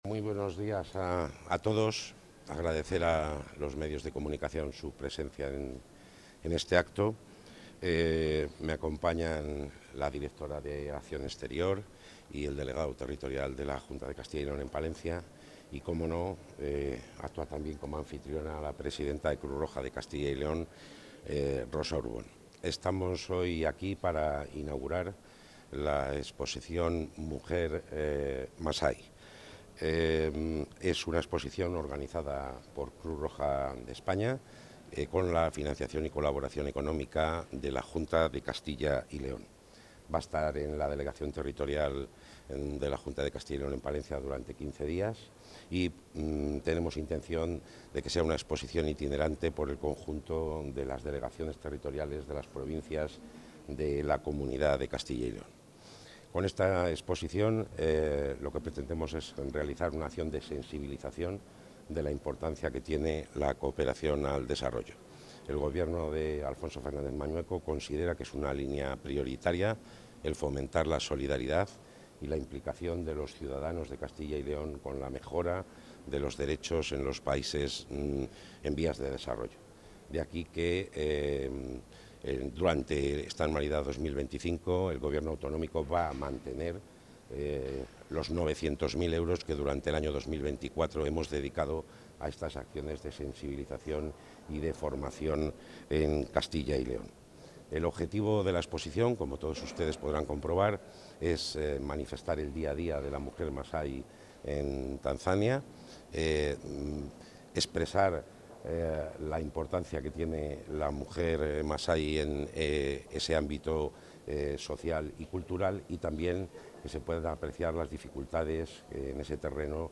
El consejero de la Presidencia, Luis Miguel González Gago, ha participado hoy en la inauguración de la exposición ‘Mujer...
Declaraciones del consejero.